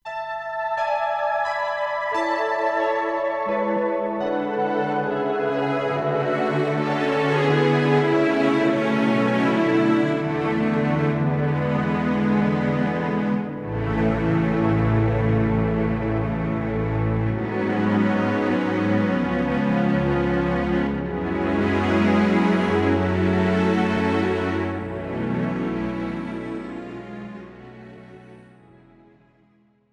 This is an instrumental backing track cover.
• Key – A, C, D♭
• Without Backing Vocals
• No Fade